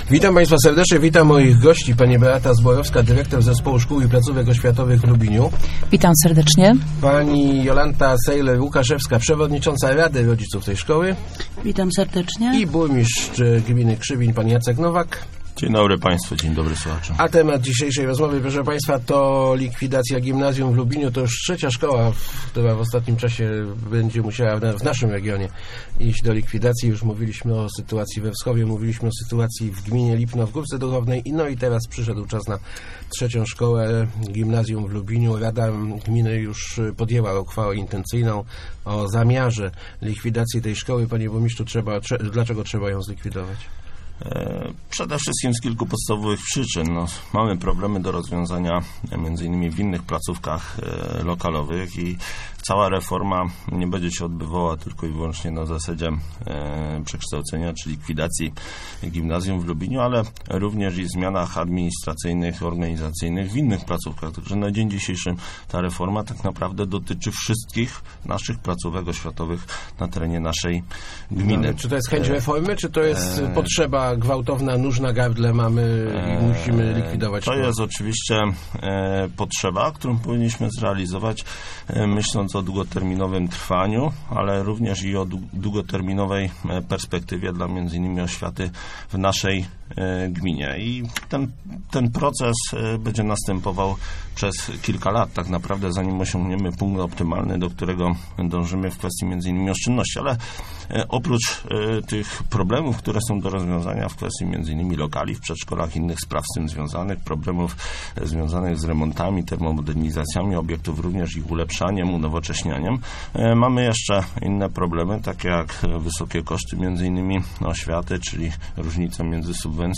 Gimnazjum w Lubiniu jest najdroższą szkołą w gminie - mówił w Rozmowach Elki Jacek Nowak, burmistrz Krzywinia. Przekonywał on, że na likwidacji szkoły gmina zaoszczędzi ok. 850 tys. złotych rocznie.